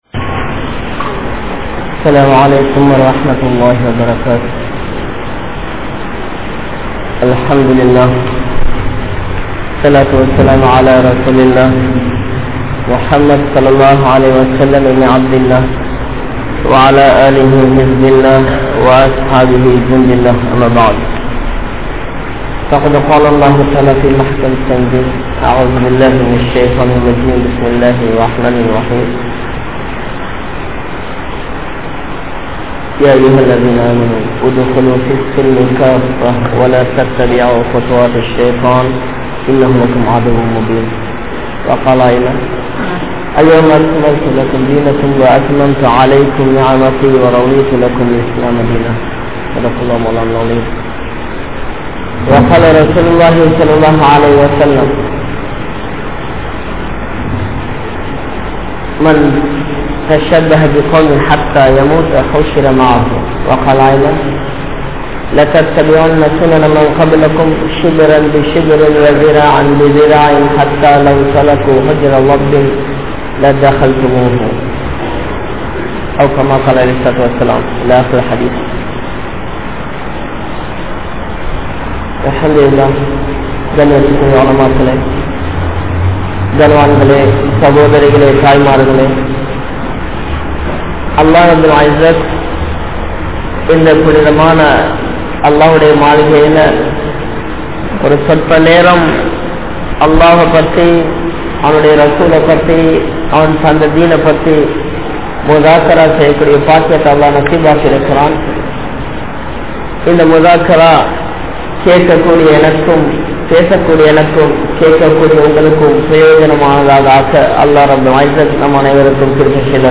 Indraya Pengalum Hijabum | Audio Bayans | All Ceylon Muslim Youth Community | Addalaichenai